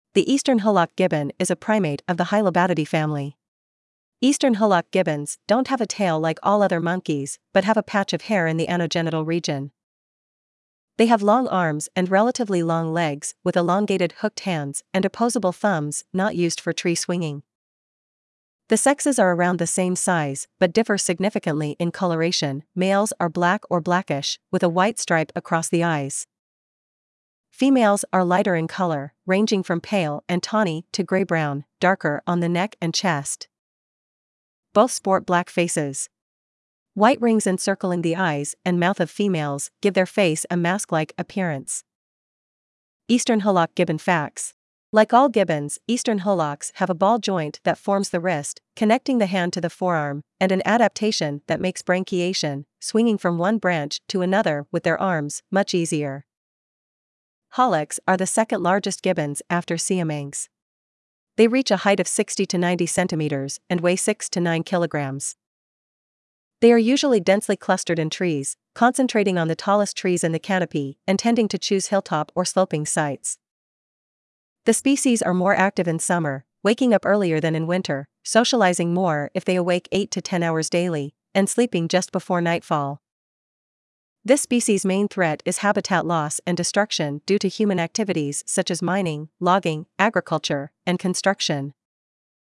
Eastern Hoolock Gibbon
eastern-hoolock-gibbon.mp3